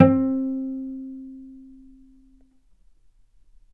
vc_pz-C4-mf.AIF